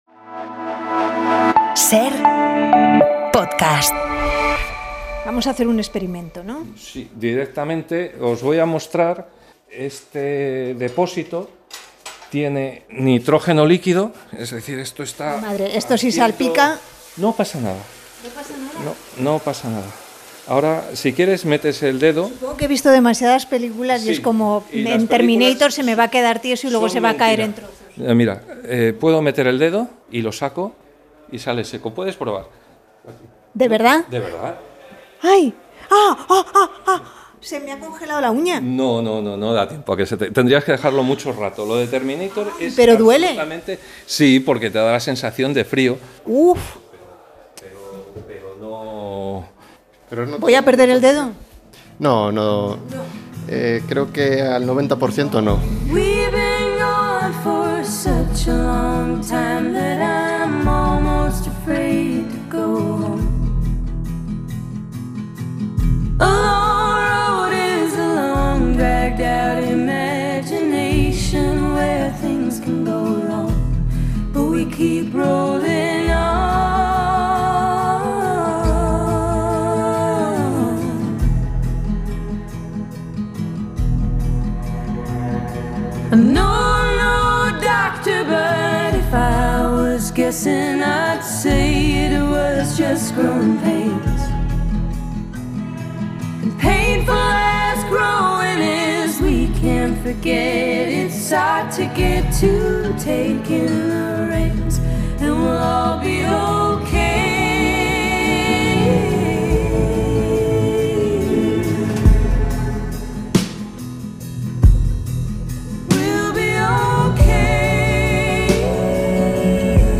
Javier del Pino y Juan José Millás discuten por la importancia del tenis frente a la petanca.